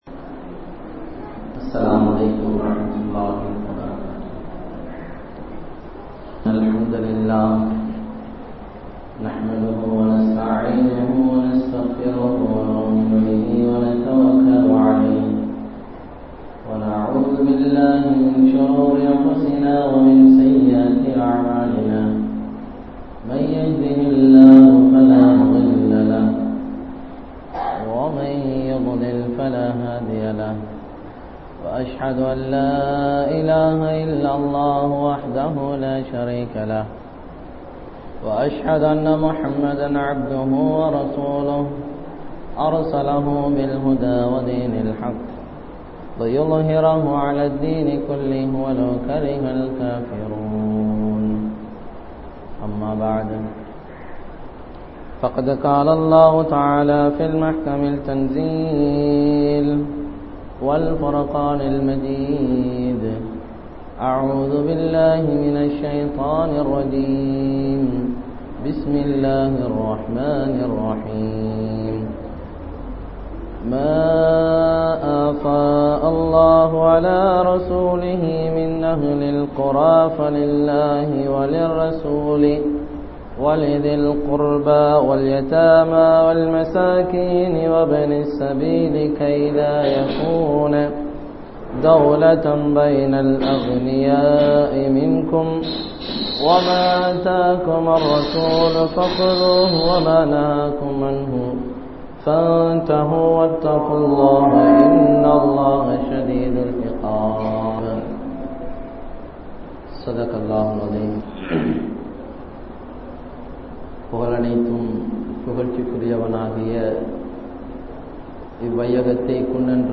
Nabi(SAW) Avarhalai Marantha Samooham (நபி (ஸல்) அவர்களை மறந்த சமூகம்) | Audio Bayans | All Ceylon Muslim Youth Community | Addalaichenai